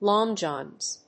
アクセントlóng jòhns